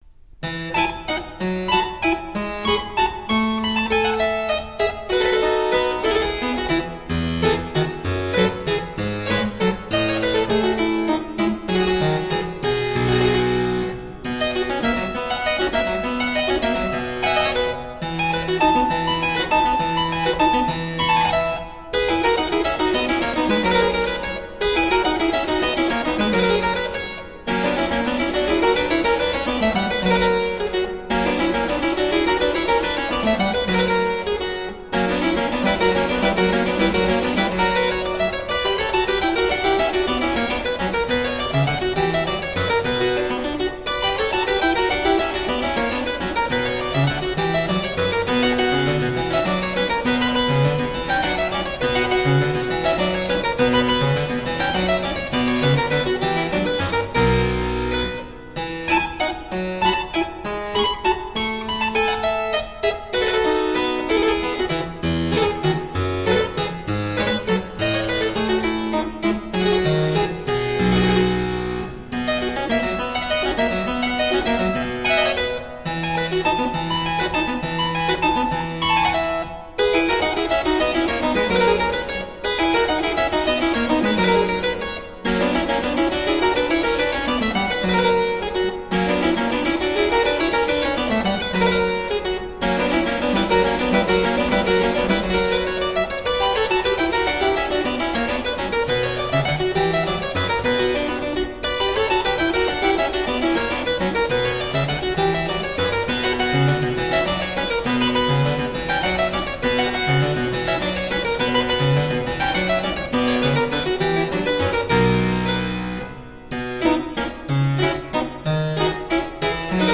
Sonate pour clavecin Kk 297 : Moderato